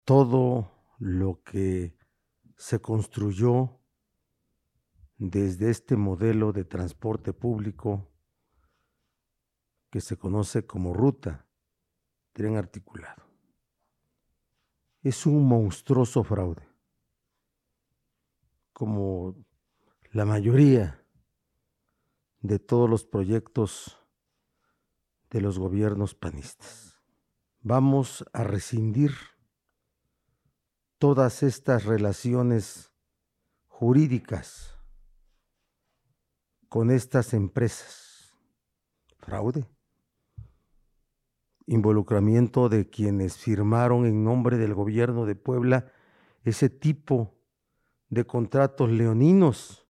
En videoconferencia de prensa en Casa Aguayo, el titular del Ejecutivo resaltó que al igual y como sucedió con otras obras, gobiernos anteriores utilizaron dicho proyecto de transporte para cometer fraudes.